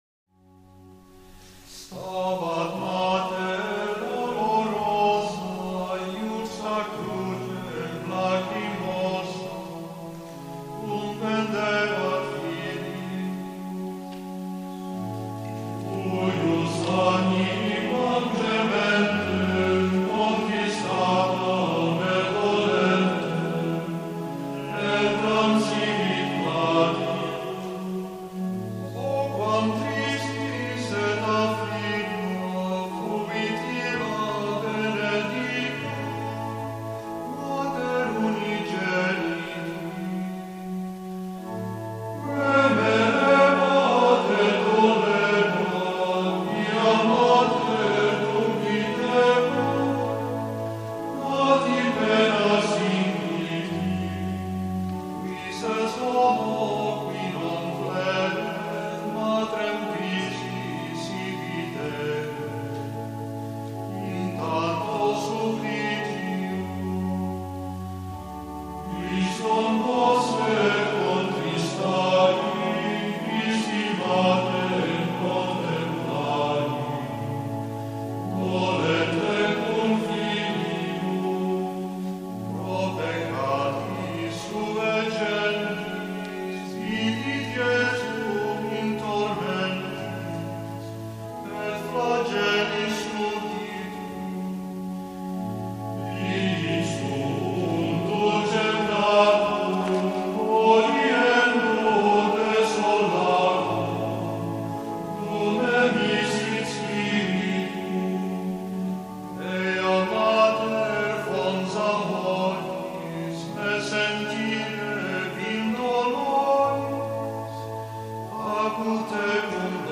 How Gregorian Chant Benefits the Health of the Body…and Soul